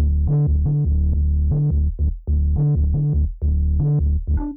000-bass.wav